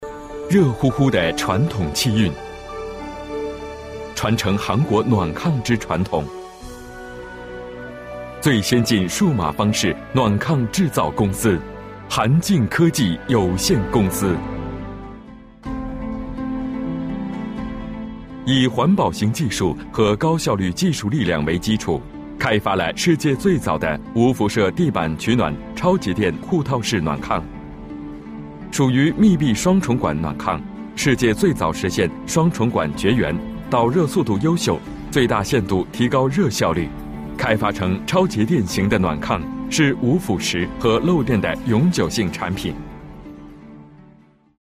성우샘플
신뢰/묵직